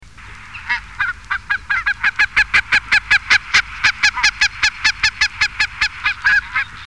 Bernache nonnette
Branta leucopsis
bernache.mp3